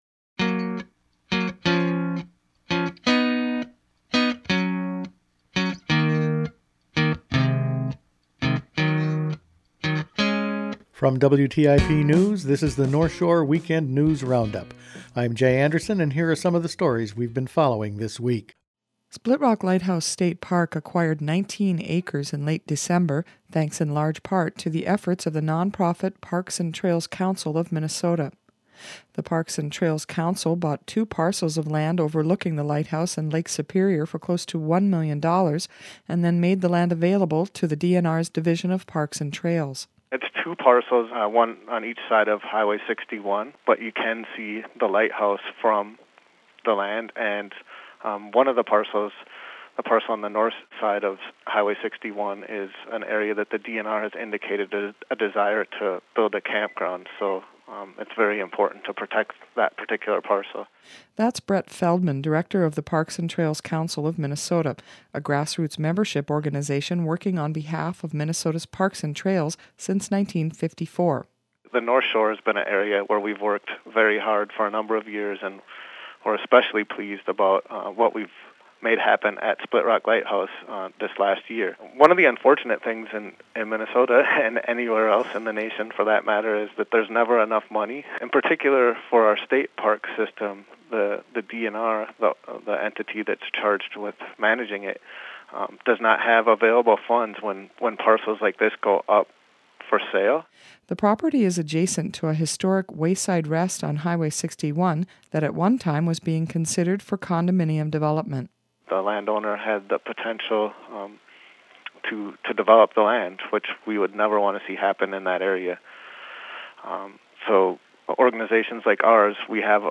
Attachment Size WrapFinalCut_011411.mp3 27.59 MB Each weekend WTIP news produces a round up of the news stories they’ve been following this week. Life on IsleRoyale, tax reimbursements, local grant recipients and potential pool problems were in this week’s news.